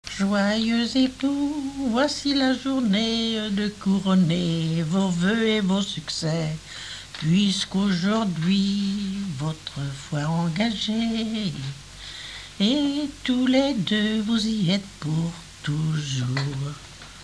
chanson de mariage
Genre laisse
Pièce musicale inédite